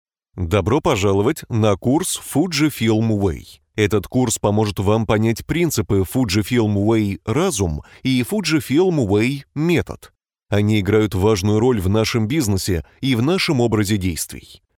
Bilingual Russian and Ukrainian Voice Over Talent with own studio
Sprechprobe: eLearning (Muttersprache):